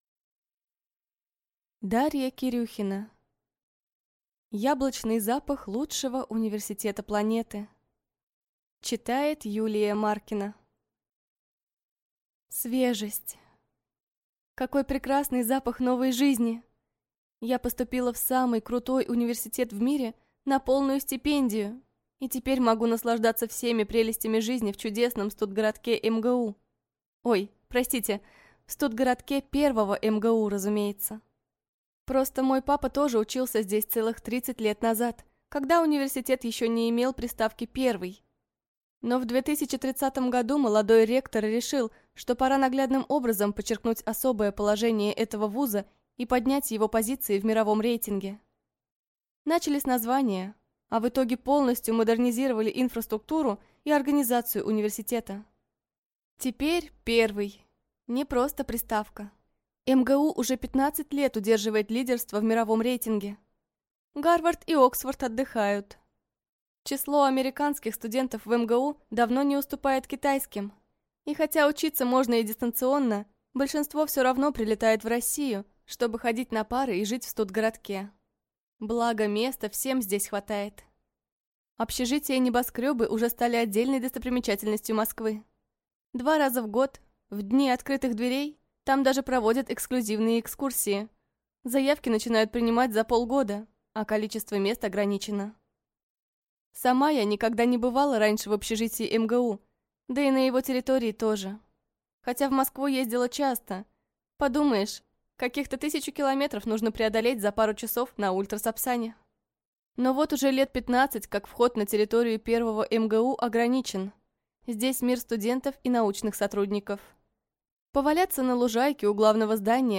Аудиокнига Яблочный запах лучшего университета планеты | Библиотека аудиокниг